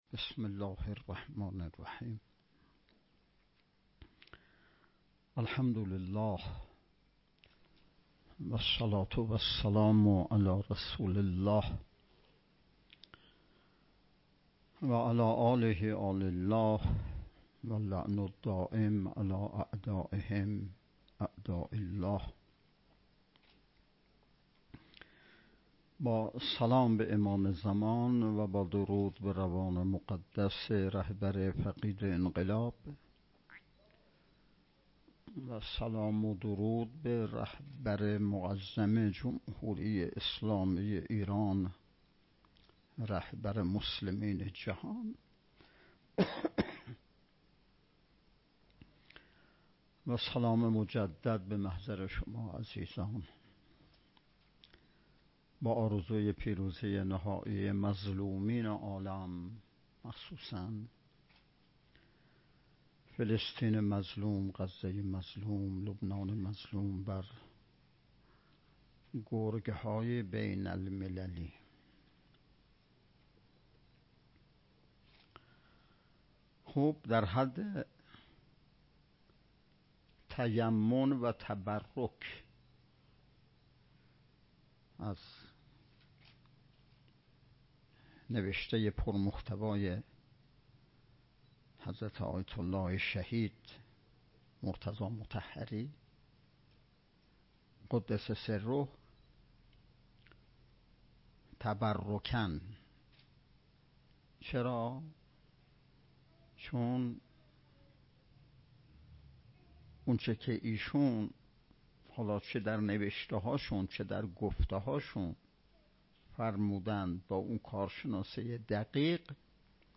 یازدهمین نشست ارکان شبکه تربیتی صالحین بسیج با موضوع تربیت جوان مؤمن انقلابی پای کار، صبح امروز (۲۲ آذر) با حضور و سخنرانی نماینده ولی فقیه در استان، برگزار شد.